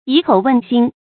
以口问心 yǐ kǒu wèn xīn
以口问心发音